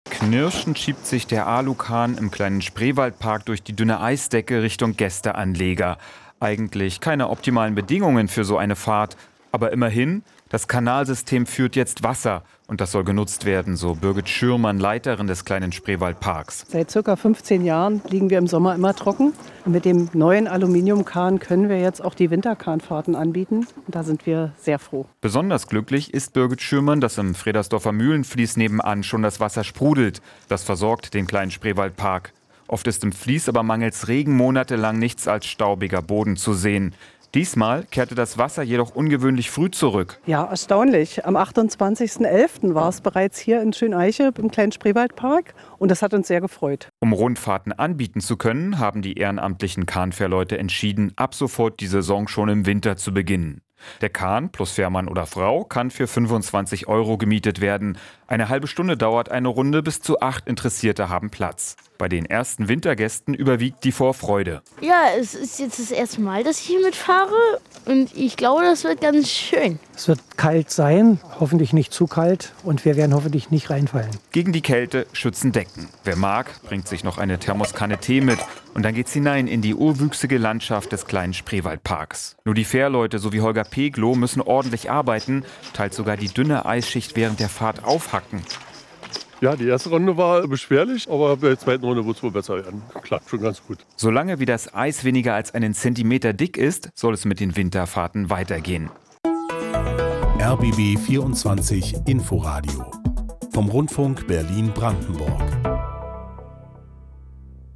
Kahnfahren auf kleinen Fließen im Winter - das ist jetzt im Kleinen Spreewaldpark von Schöneiche möglich. Zwischen Erkner und Rüdersdorf ist auch in der eiskalten Jahreszeit ein Kahn unterwegs.